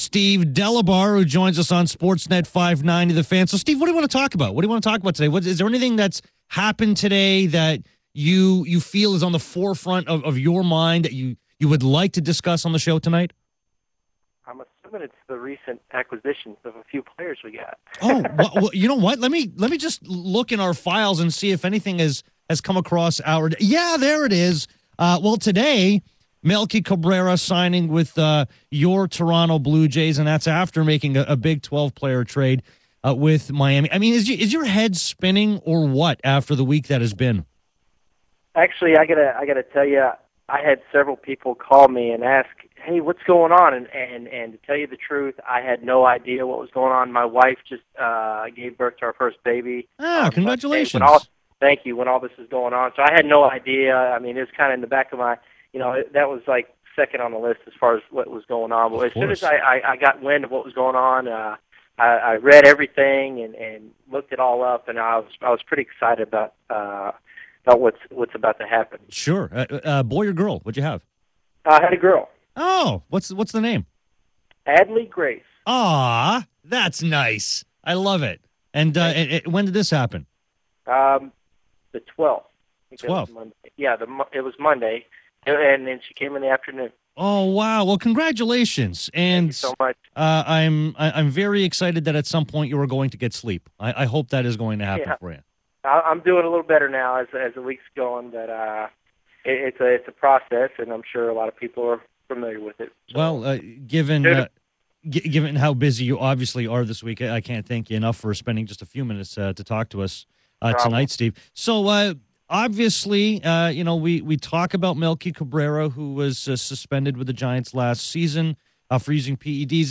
9:49 interview from last night